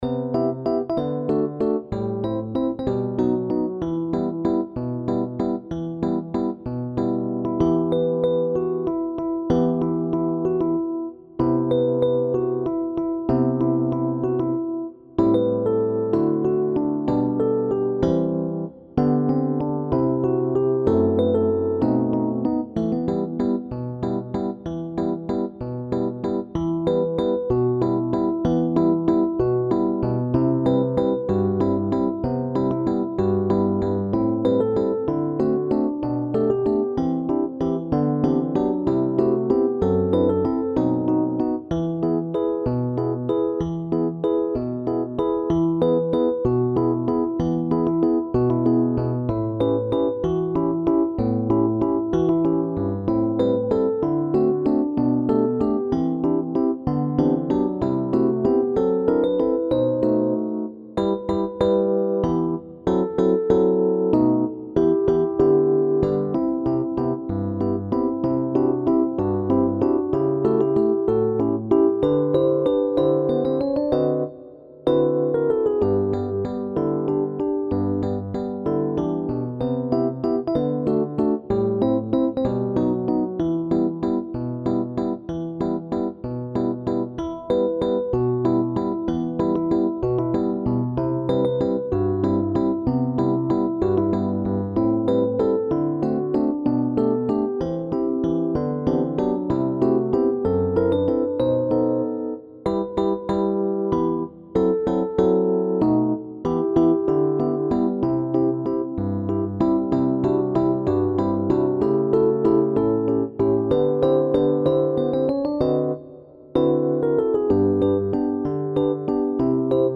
SSATB con solo